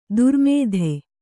♪ durmēdhe